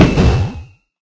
sounds / mob / enderdragon / hit3.ogg
hit3.ogg